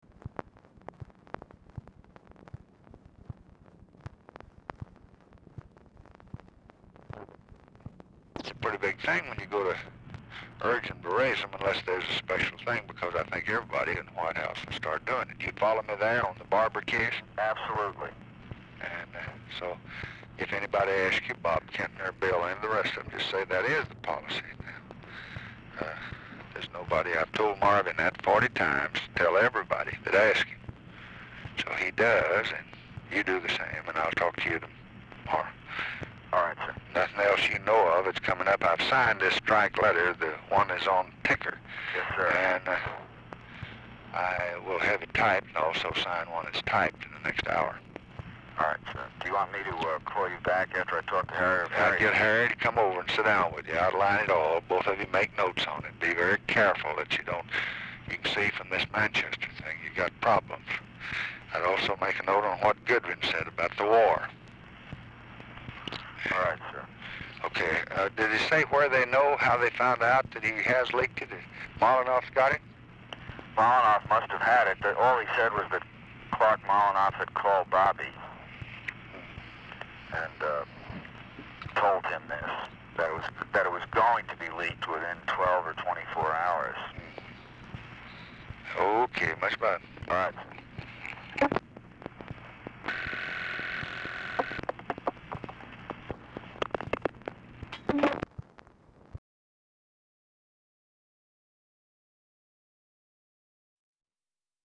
Format Dictation belt
Location Of Speaker 1 LBJ Ranch, near Stonewall, Texas
Specific Item Type Telephone conversation Subject Appointments And Nominations Business Labor Press Relations White House Administration